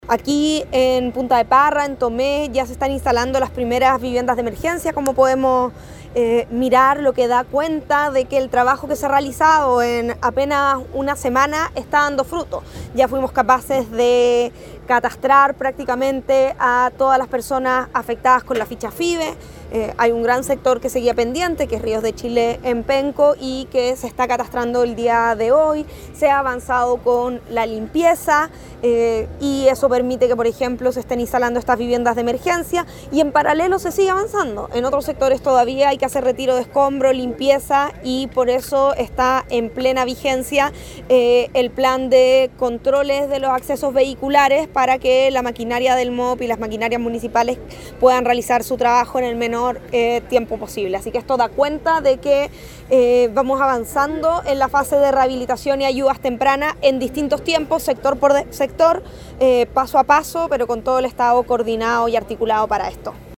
Desde ese lugar, la ministra de Desarrollo Social, Javiera Toro, explicó que el trabajo ha ido avanzando y superando etapas en distintos sectores, tanto en levantamiento de información, a través de las Fichas FIBE, limpieza y remoción de escombros y la reconstrucción de estas viviendas.